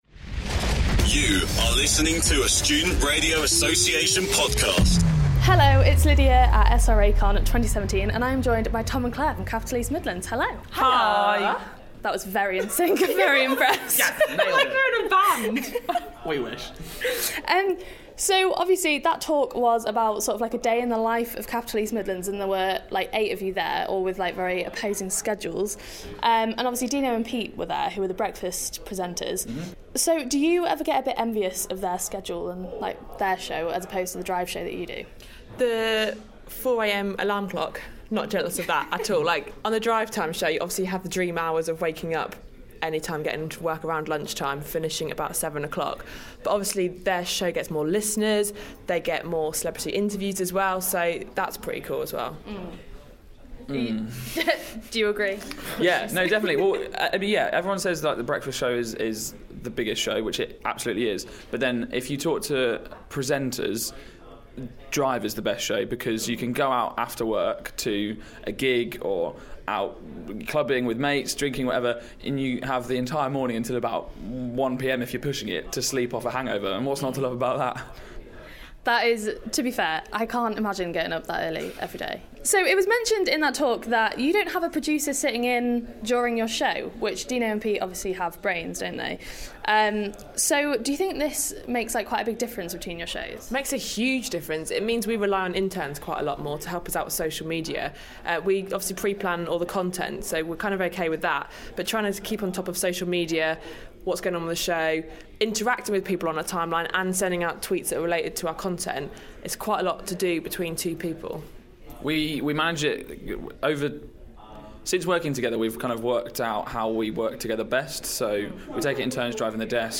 We hear from the Capital East Midlands Drivetime team on what their schedule is like.